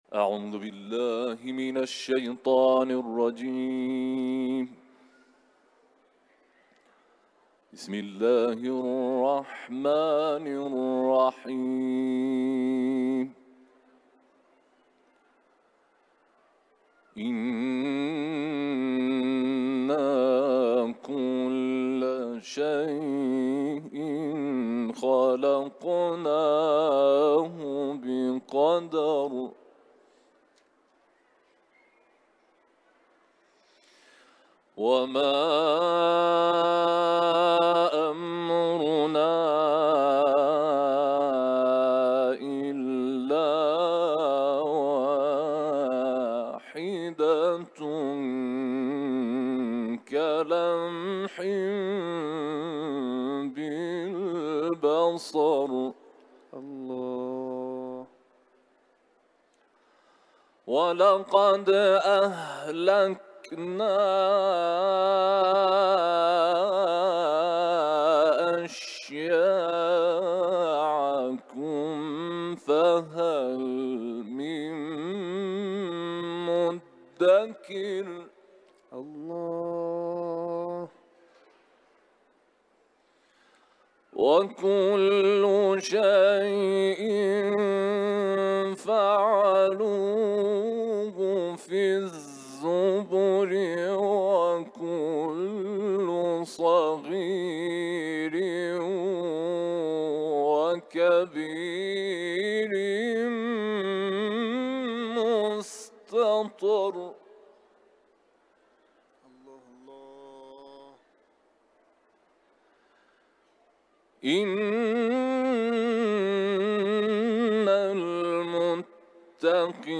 در محفل قرآنی حرم مطهر حضرت رضا(ع)
تلاوت قرآن